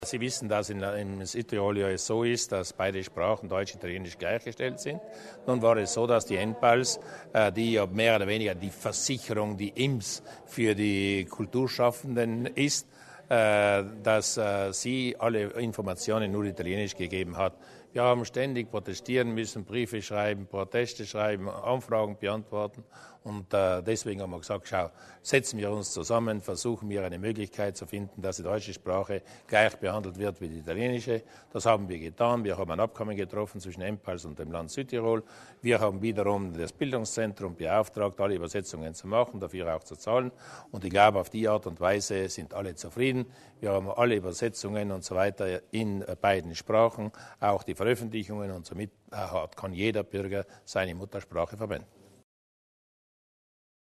Landeshauptmann Durnwalder zur Bedeutung des Abkommens